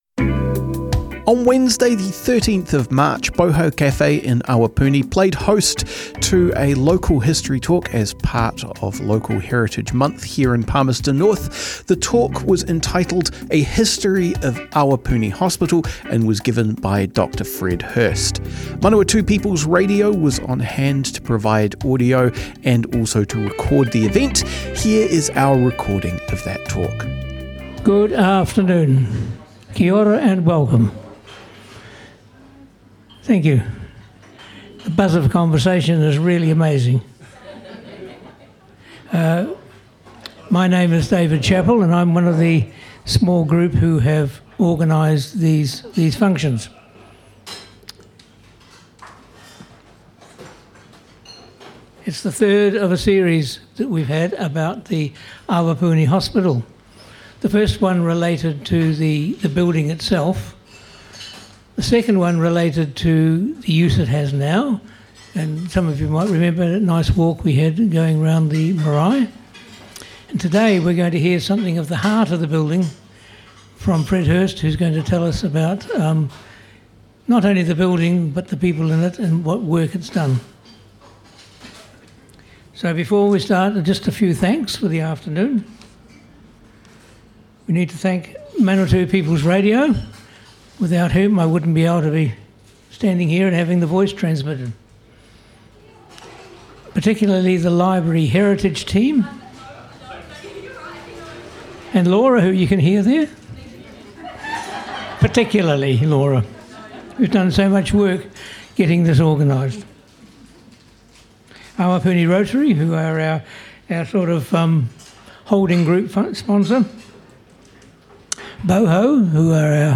This talk was captured with the kind support of MPR, Manawatū People's Radio at a 2024 Local History Month event.